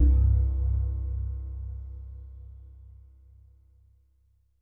LEAD C1.wav